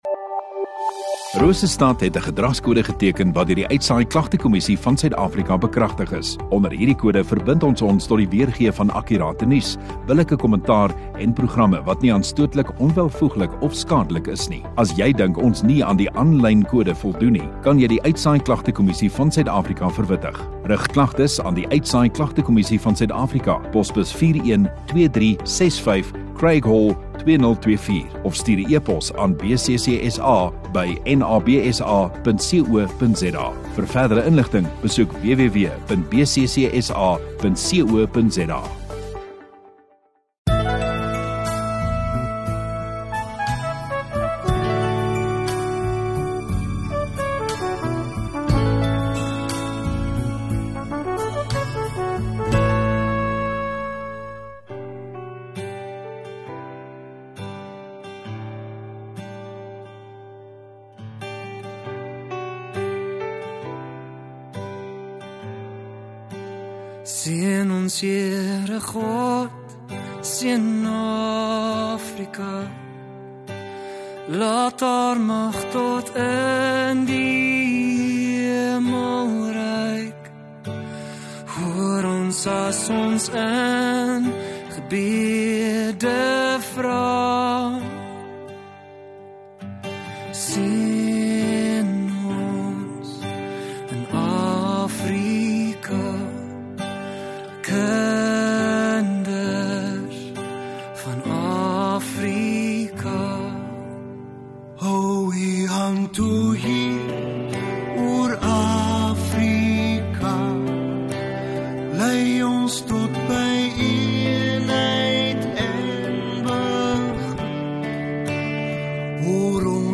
18 Aug Sondagaand Erediens